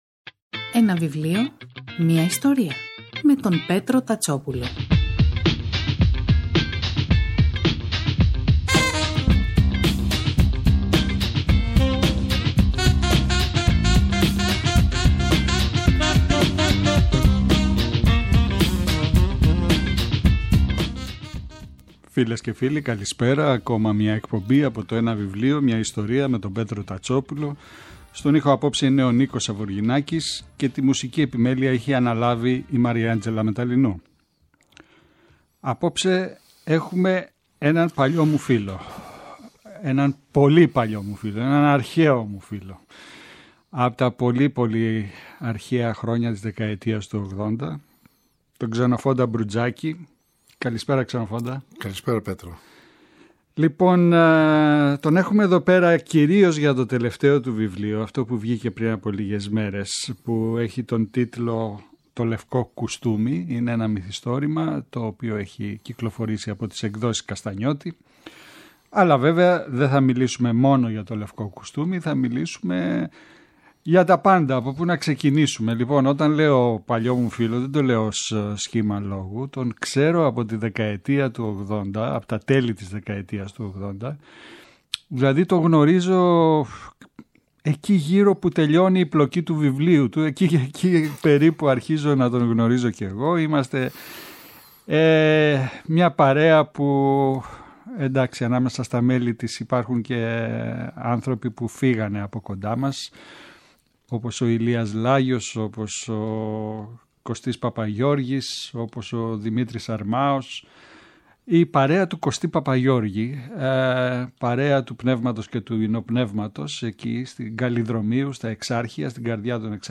σε μια συζήτηση